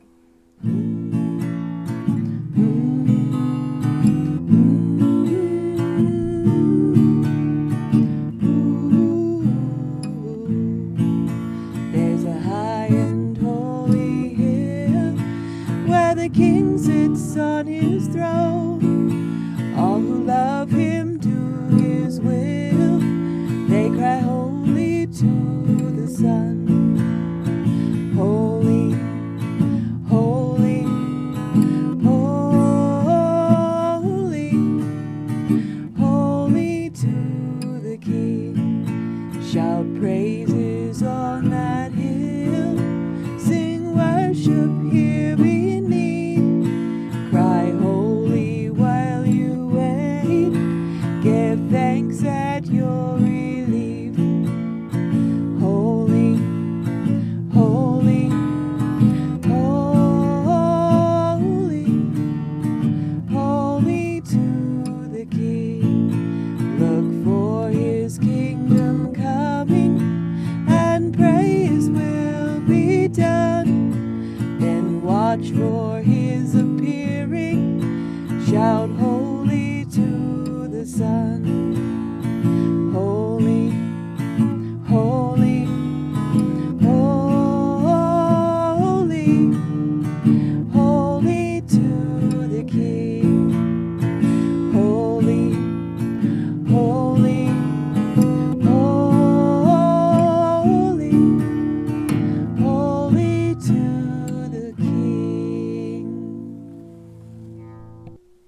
This song was fun because we would sing the chorus quietly at first, but with each "holy" we grew louder and louder until at last we were shouting.